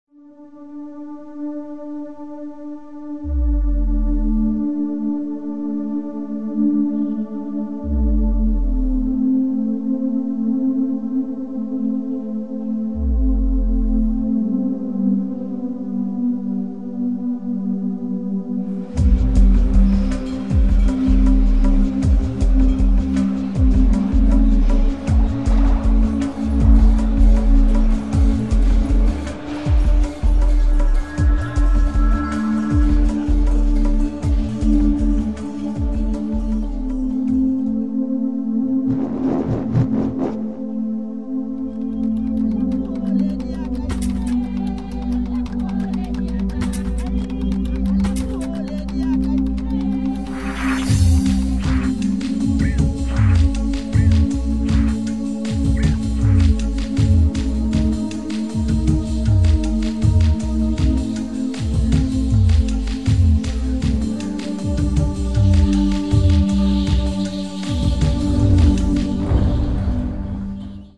"The Prodigy", a hard techno style with breakbeat